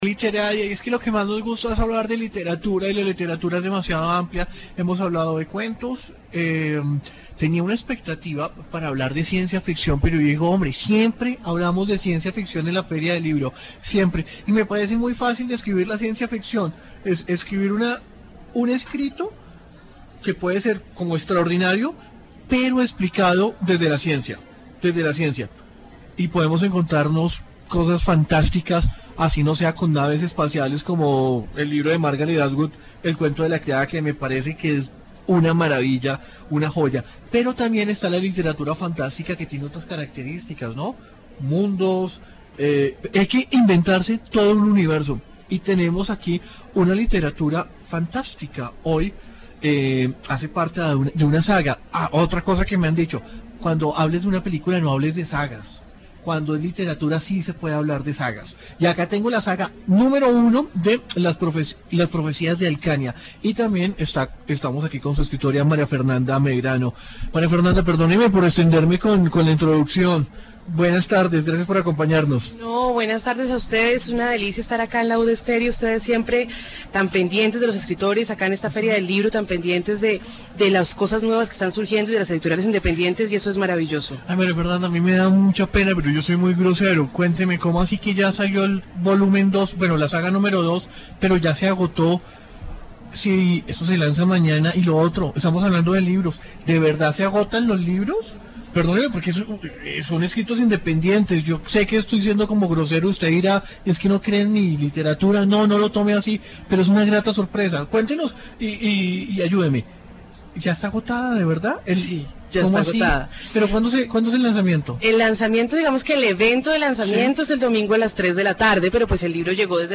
dc.subject.lembProgramas de radio